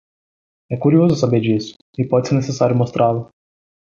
Pronunciado como (IPA)
/ku.ɾiˈo.zu/